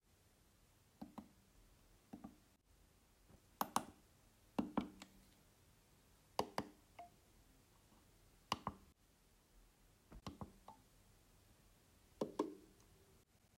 Die rechte und linke Maustaste sind flüsterleise und wirklich angenehm. Alle anderen Tasten sind allerdings lauter – und zwar um ein Vielfaches. Die mittlere Maustaste ist mit weit mehr als 10 db Unterschied am lautesten. Aber auch Vor- und Zurücktaste sind jeweils unterschiedlich laut.
Nachfolgend klicke ich in dieser Reihenfolge die Tasten durch: linke Maustaste, rechte Maustaste, mittlere Maustaste (Rad), Moduswechsel-Taste, Gesten-Taste, Vor-Taste, Zurück-Taste, Haptische Taste
logitech-mx-master4.m4a